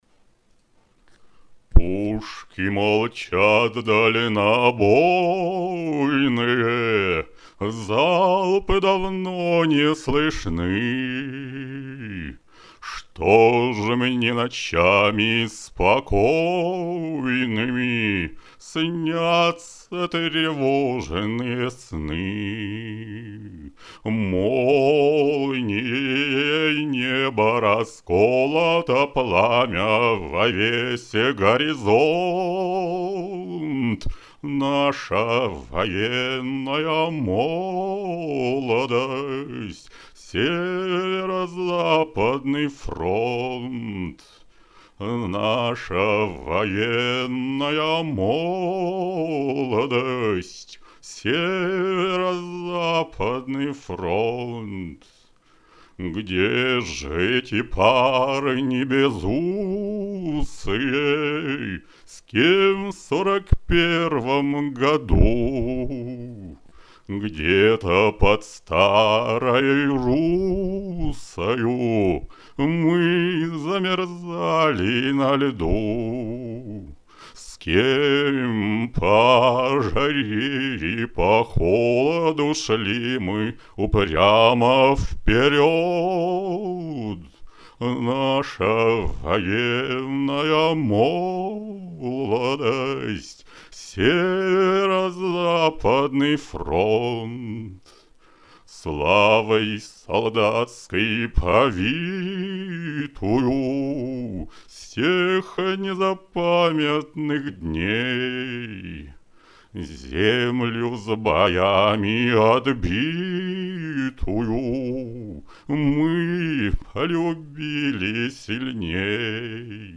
Пытаюсь как-то промычать песню.